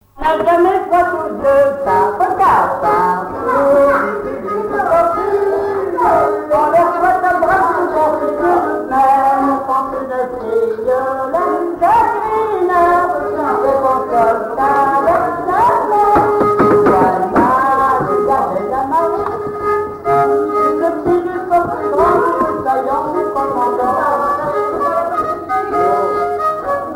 danse : marche
Genre strophique
Pièce musicale inédite